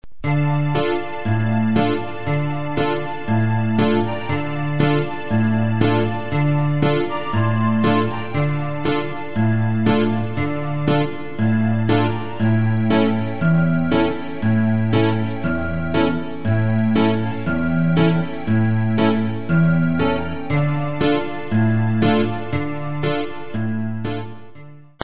Extrait musical